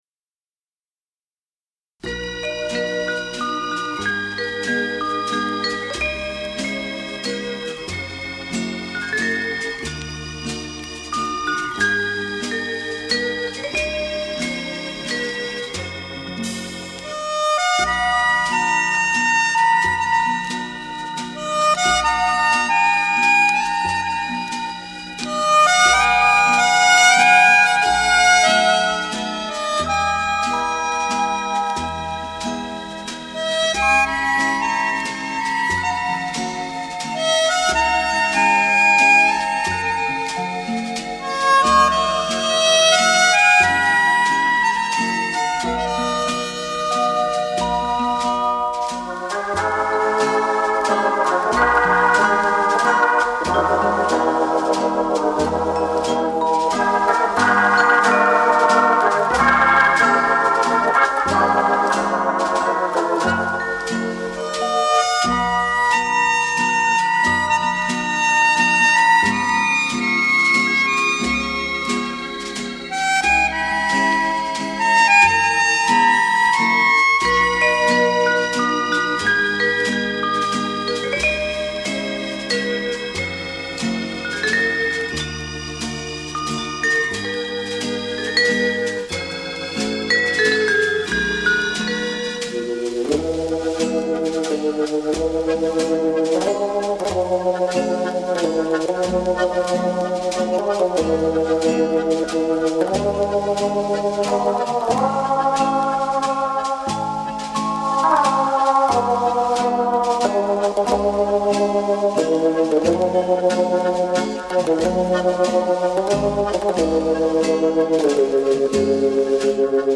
LANGSAMER WALZER